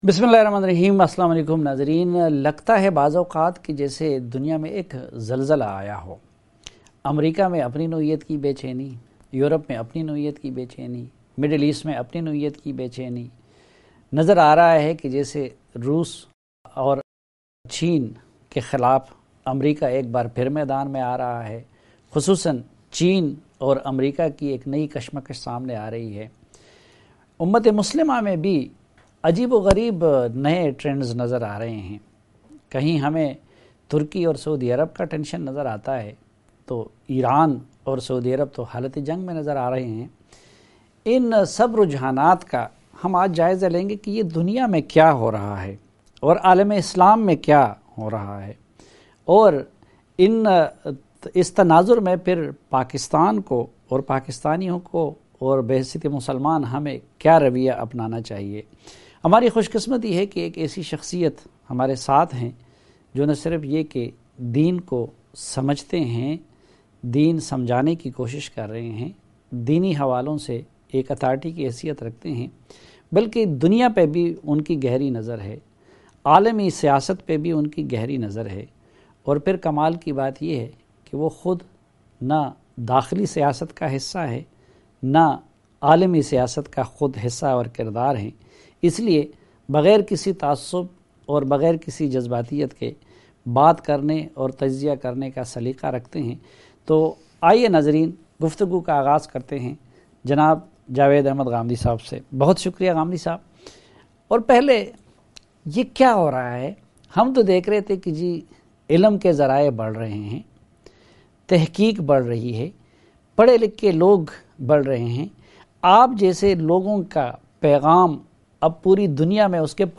Javed Ahmad Ghamidi's Comments on important issue related to politics, economy, sociology, religion and international relations. It is a journey to achieve prosperous future by providing access to bitter truth through serious and thought provoking dialogue In Geo Tv's program Jirga with Saleem Safi.